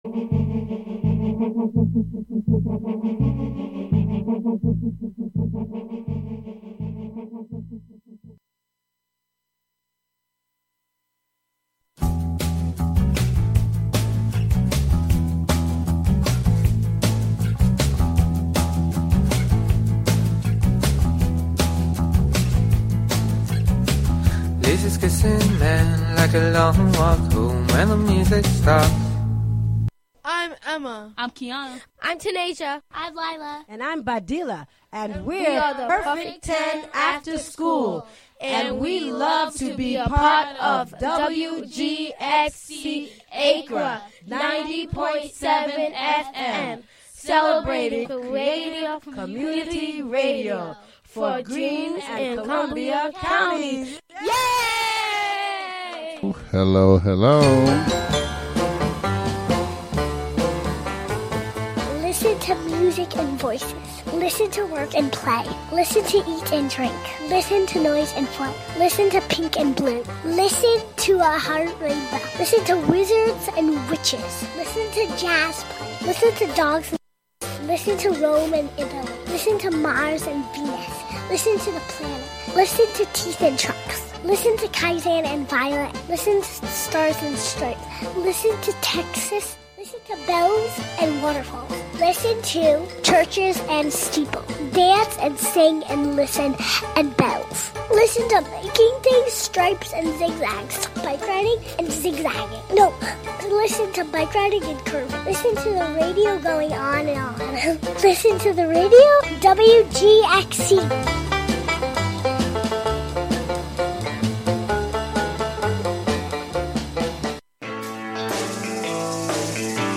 Each show, "The Radio Museum" broadcasts a collection of different voices from radio's past. True raconteurs who knew how to tell intimate, personal, engaging stories on the radio.
Plus some music from the era as well as a weekly recipe.